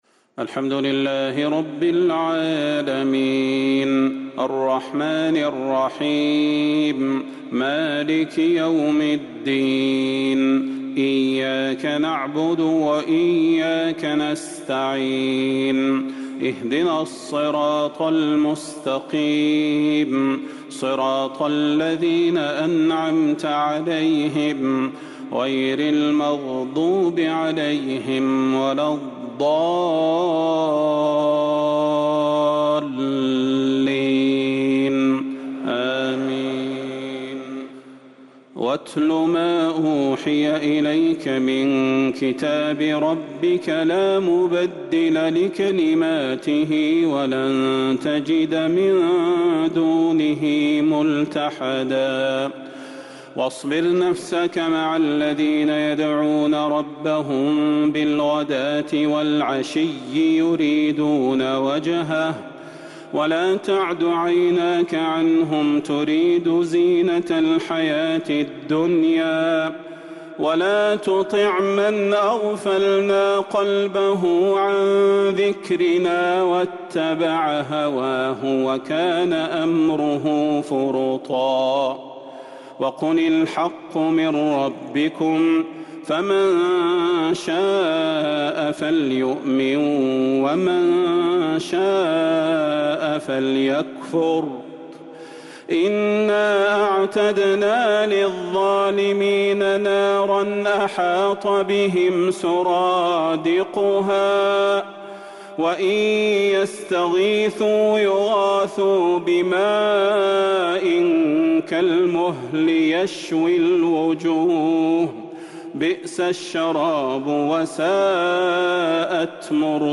تراويح ليلة 20 رمضان 1444هـ من سورة الكهف (27-82) | Taraweeh 20th night Ramadan1444H Surah Al-Kahf > تراويح الحرم النبوي عام 1444 🕌 > التراويح - تلاوات الحرمين